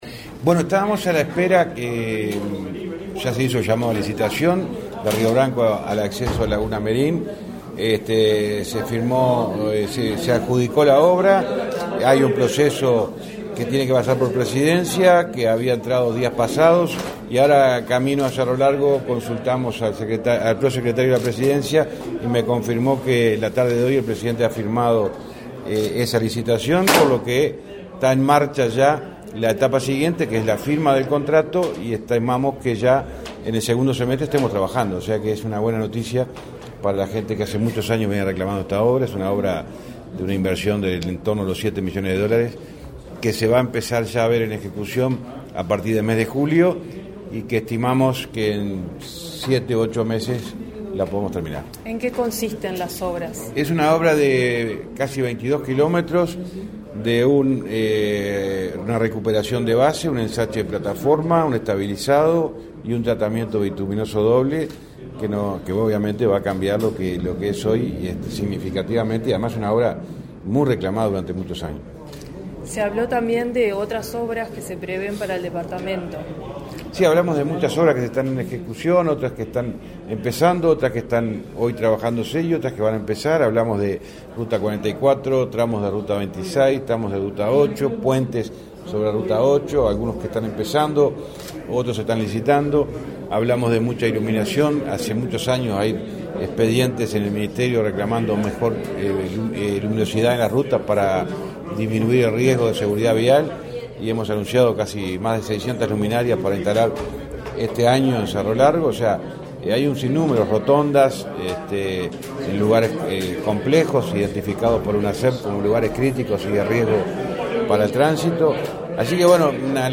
Entrevista al ministro de Transporte y Obras Públicas, José Luis Falero, por obras en Laguna Merin